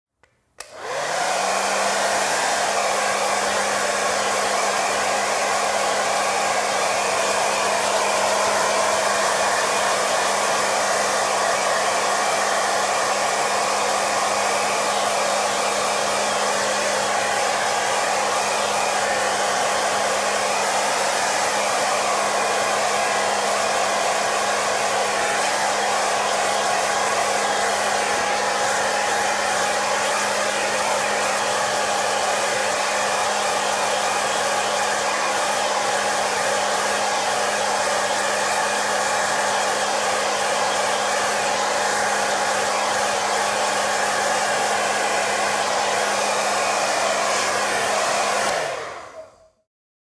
Звуки фена
На этой странице собраны различные звуки работающего фена: от тихого гула до мощного потока воздуха.
Шум работающего фена